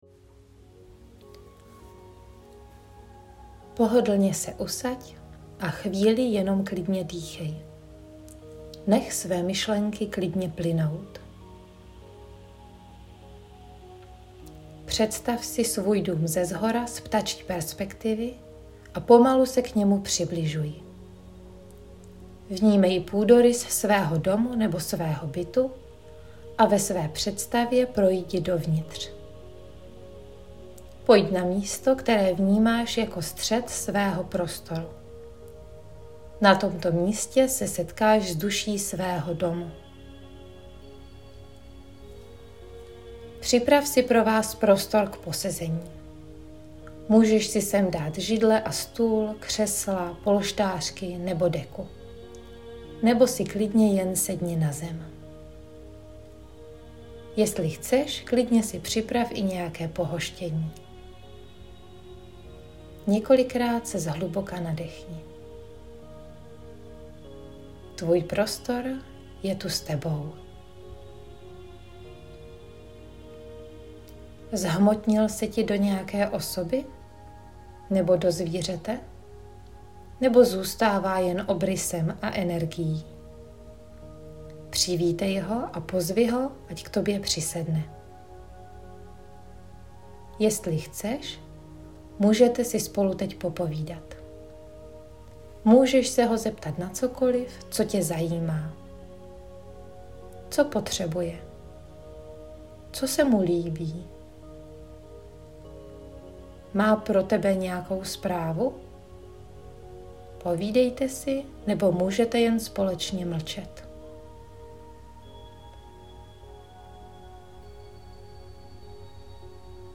Duše prostoru meditace.mp3